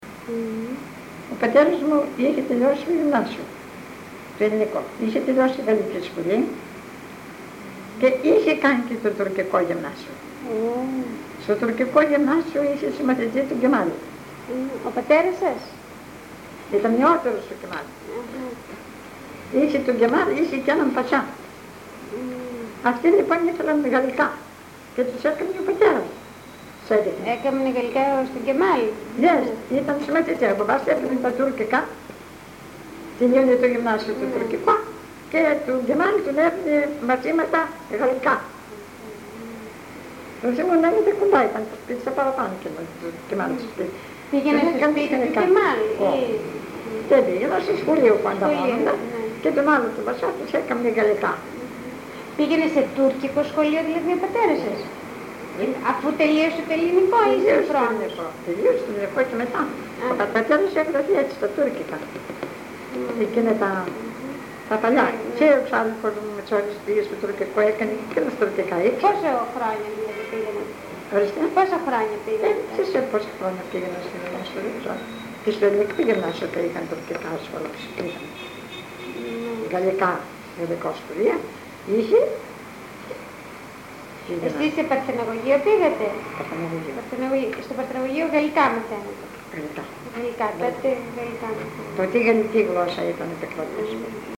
Συνεντεύξεις κατοίκων της Θεσσαλονίκης, στο πλαίσιο της προετοιμασίας της έκθεσης του ΛΕΜΜ-Θ
συνέντευξη (EL)